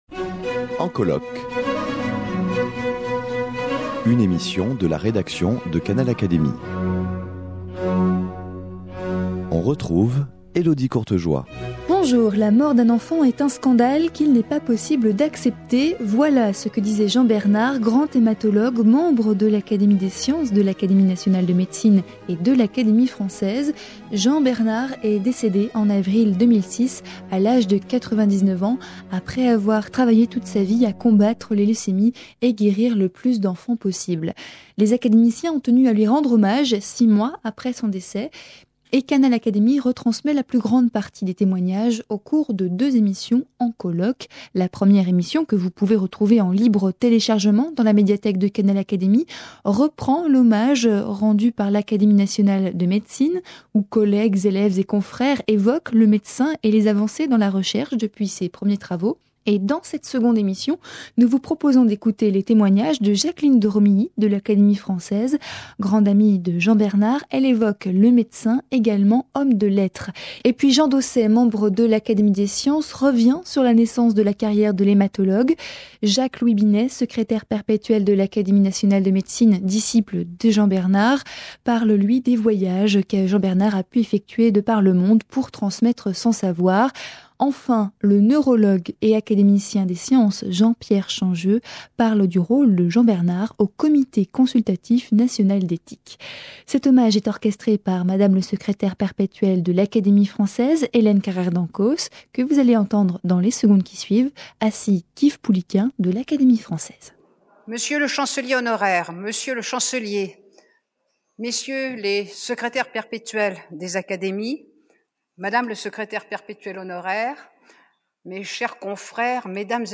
Le 17 octobre 2006, l'Académie française a rendu hommage à Jean Bernard. Lors de cette après-midi, les trois académies où siéga Jean Bernard, furent représentées :- l'Académie française par Jacqueline Worms de Romilly- l'Académie des sciences par Jean Dausset et Jean-Pierre Changeux- l'Académie nationale de médecine par son secrétaire perpétuel Jacques-Louis Binet. Canal Académie retransmet les communications de ces académiciens.